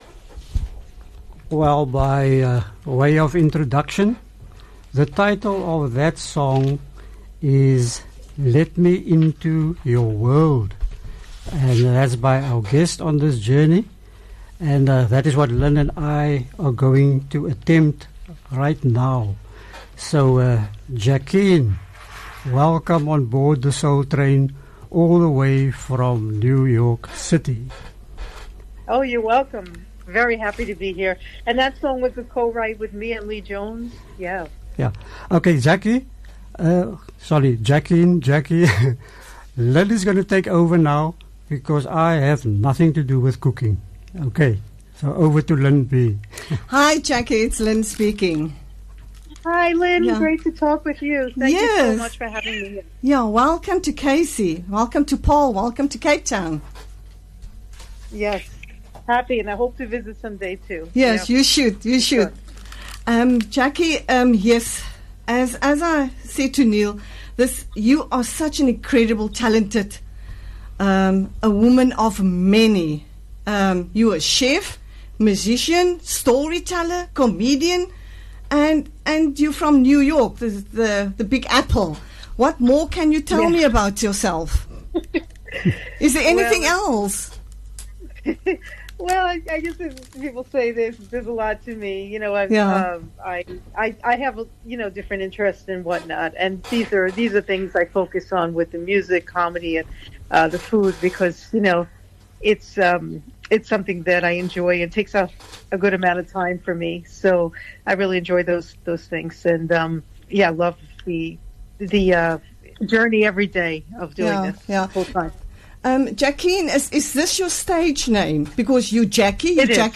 Live Interview with Musician and Songs